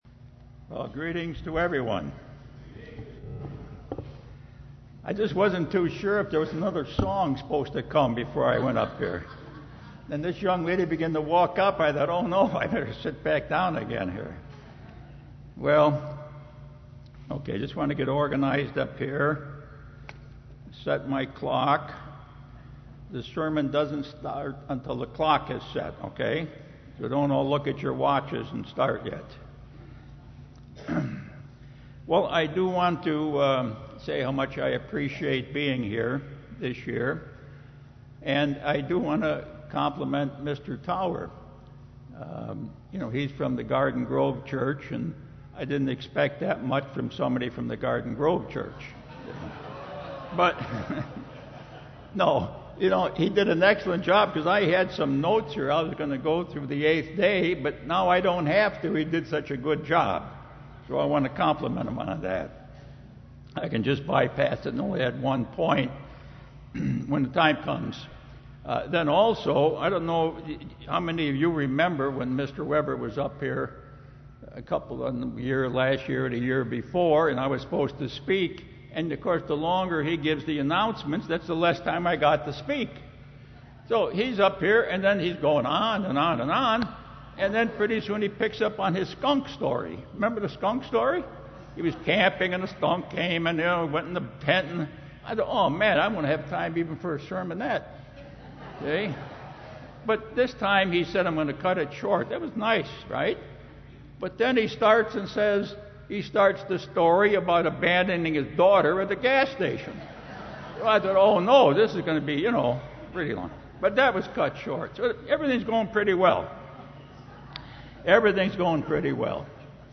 This sermon was given at the Oceanside, California 2018 Feast site.